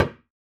Percs
PERC - DIP AND DOT.wav